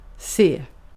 Swedish Central Standard[48][49] se [s̪eː] 'see' Often diphthongized to [eə̯] (hear the word:
[s̪eə̯]).